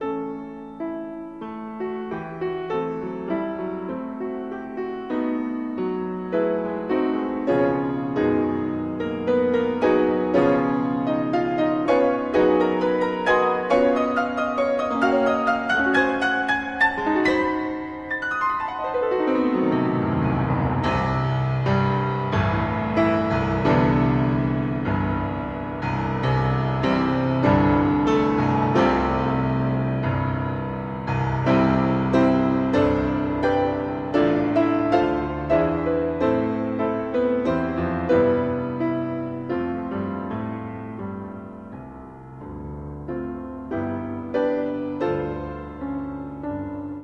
klavír